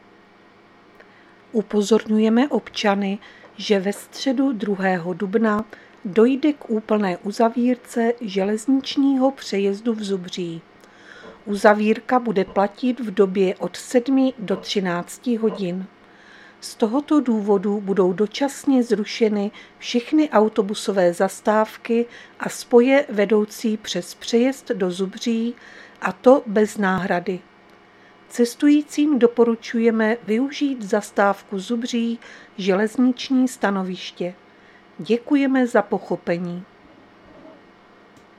Záznam hlášení místního rozhlasu 31.3.2025
Zařazení: Rozhlas